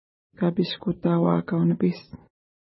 ID: 54 Longitude: -59.4511 Latitude: 52.8274 Pronunciation: ka:piʃkuta:wa:ka:w-nəpi:s Translation: Lump Shaped Mountain Lake (small) Feature: lake Explanation: Named in reference to nearby mountain Kapishkutauakat (no 56).